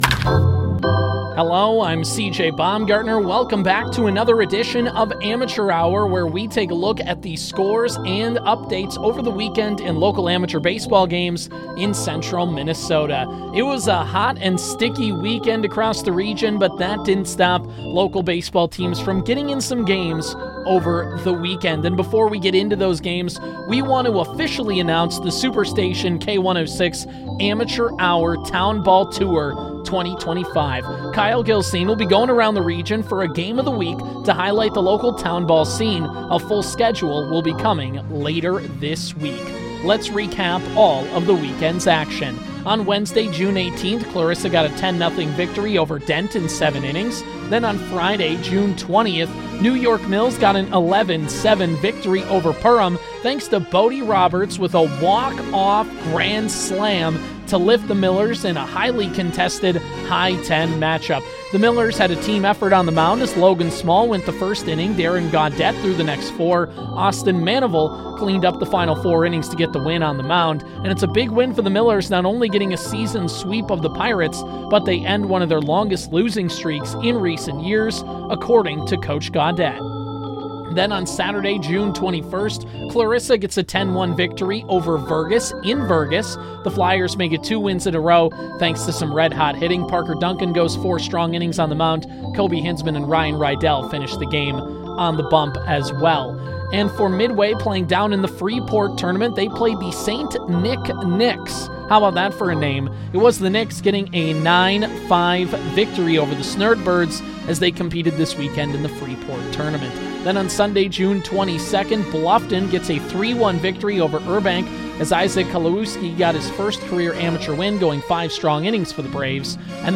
the bi-weekly town baseball segment covering all of the squads across central Minnesota